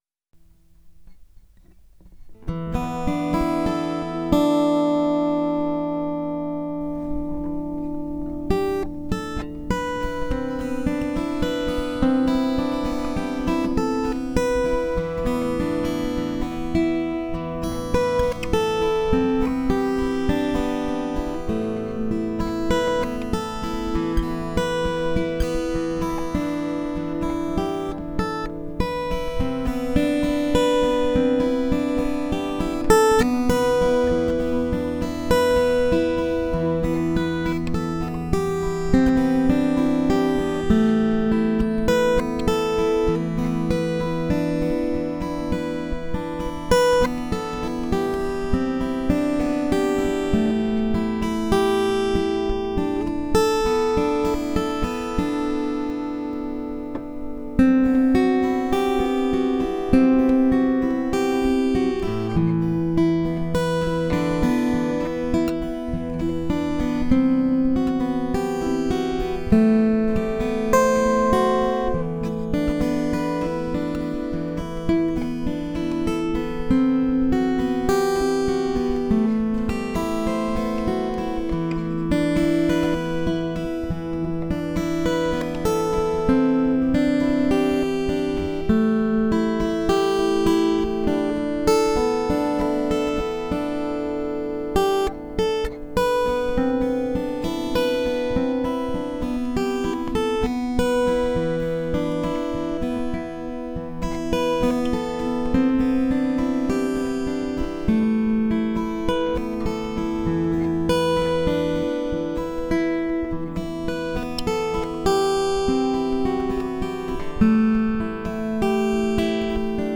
Méditation L’Orbe D’or
C’est là dans la musique, tout autour de vous un profond sentiment de sécurité et de confort – c’est l’énergie de l’univers entier qui vous soutient.